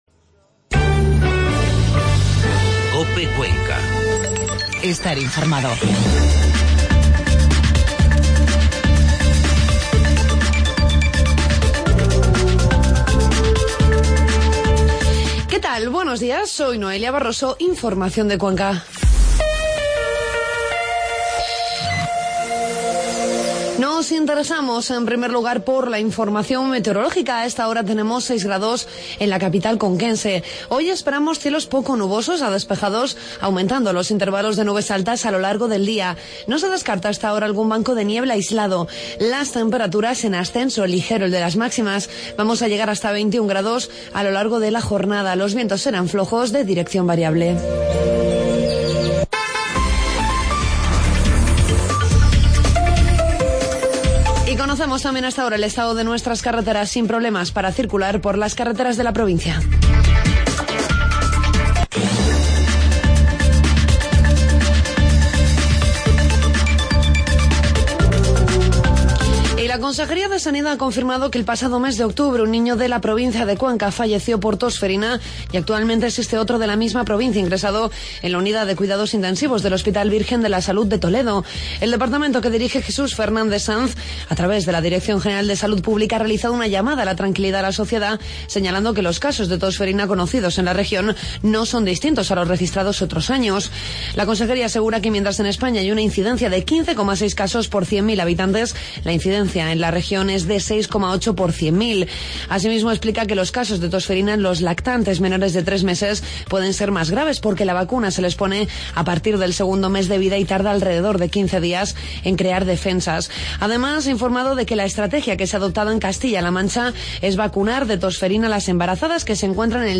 Informativo matinal COPE Cuenca